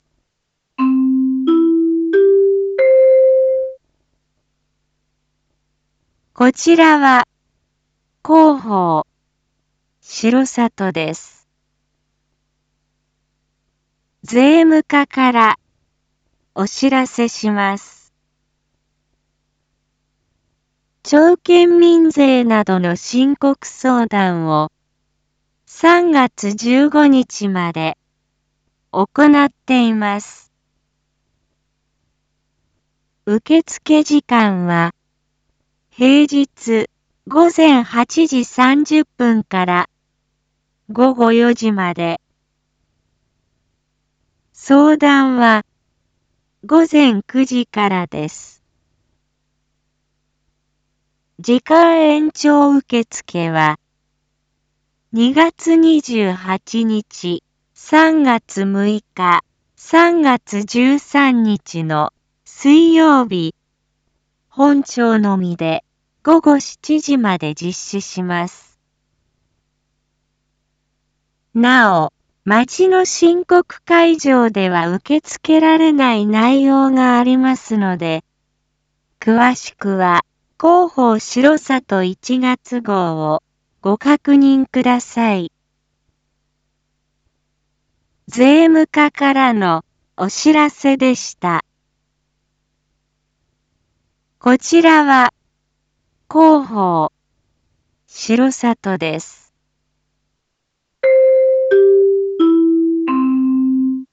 一般放送情報
Back Home 一般放送情報 音声放送 再生 一般放送情報 登録日時：2024-02-22 07:01:46 タイトル：申告相談B① インフォメーション：こちらは広報しろさとです。